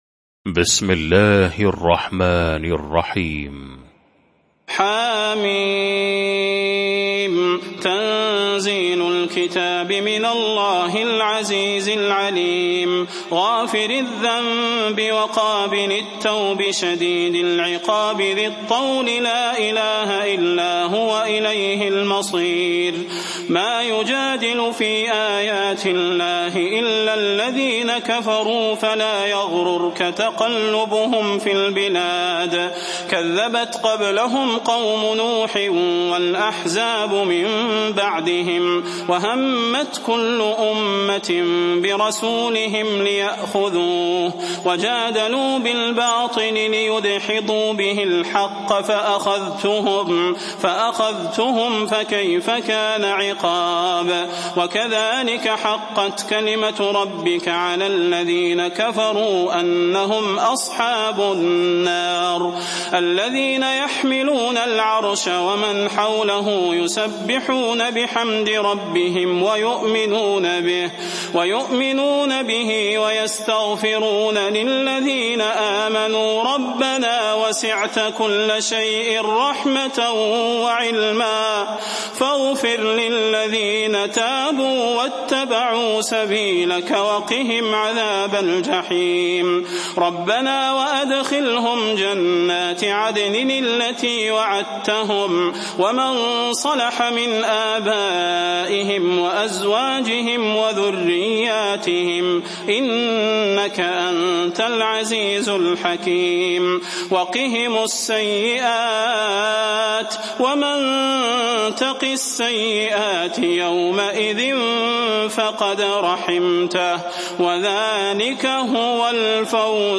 المكان: المسجد النبوي الشيخ: فضيلة الشيخ د. صلاح بن محمد البدير فضيلة الشيخ د. صلاح بن محمد البدير غافر